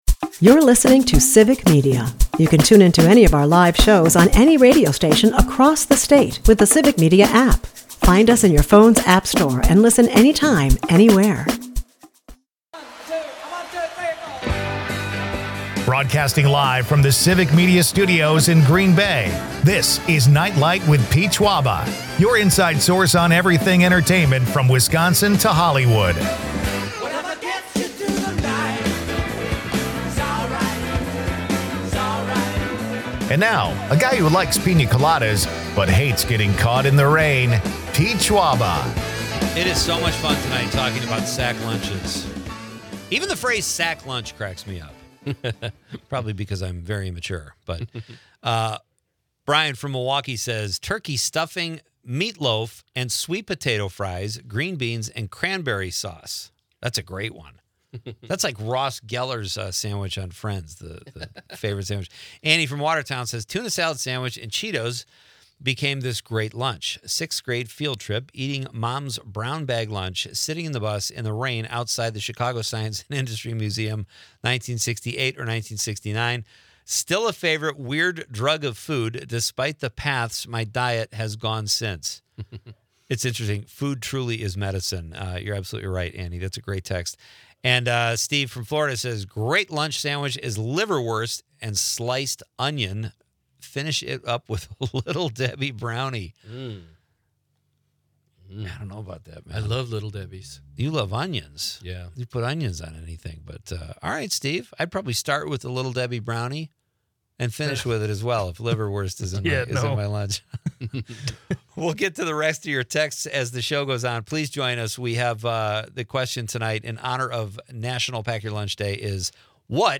Leave the stress of the day behind with entertainment news, comedy and quirky Wisconsin.
Fantasy Baseball Talk (Hour 2) March 10